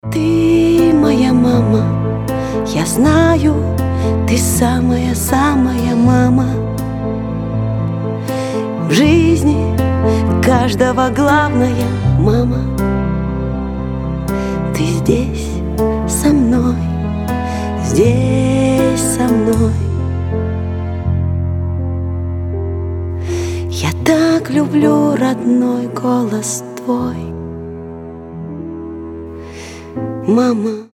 женский вокал
OST
нежные
трогательные